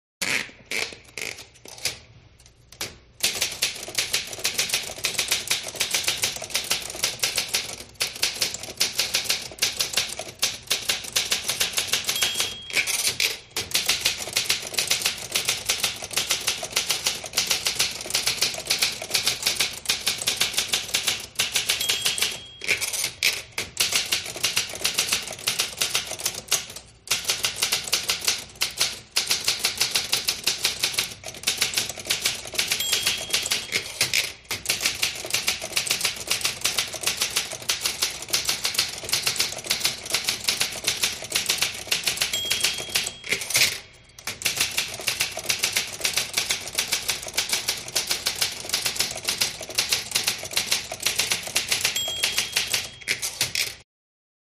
Здесь собраны характерные эффекты: от ритмичного стука клавиш до металлического звона каретки.
Звук печатной машинки с набором текста и кареткой